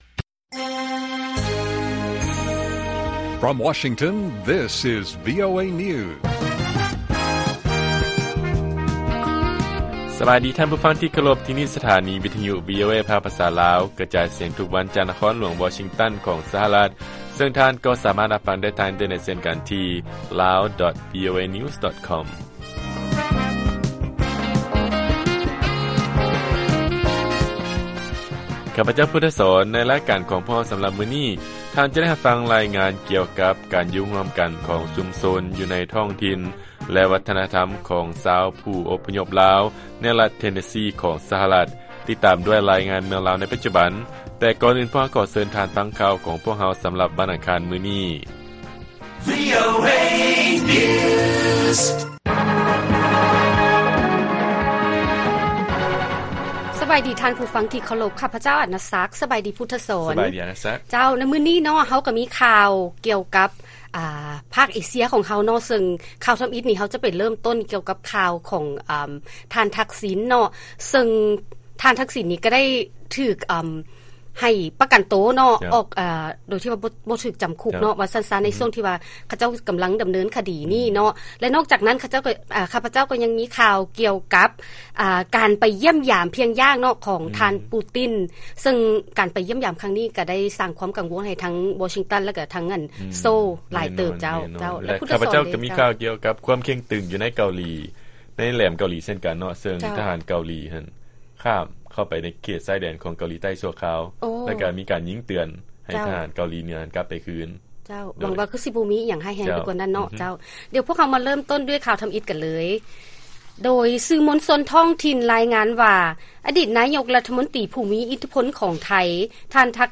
ລາຍການກະຈາຍສຽງຂອງວີໂອເອລາວ: ທັກສິນ ໄດ້ຮັບການປະກັນໂຕ, ຄະດີຕ່າງໆຂອງສານໄທ ເພີ້ມຄວາມສ່ຽງດ້ານວິກິດການທາງດ້ານການເມືອງ